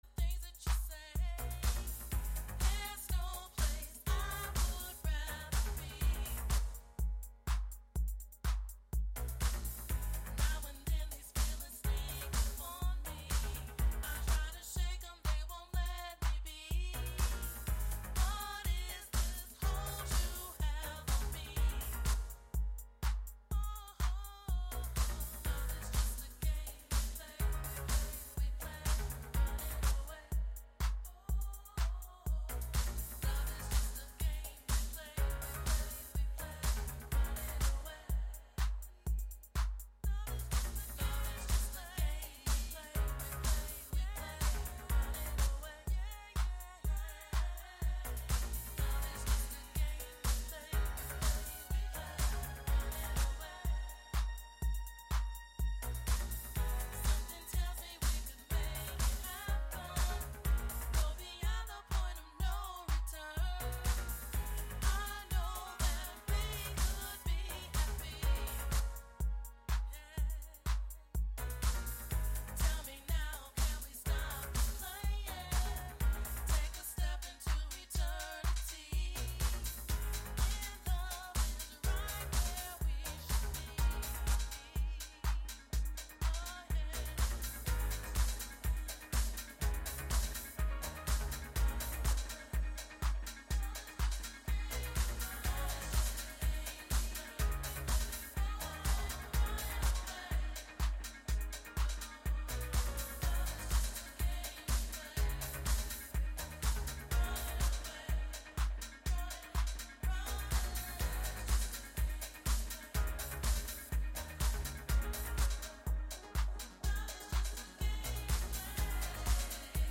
perform live on the patio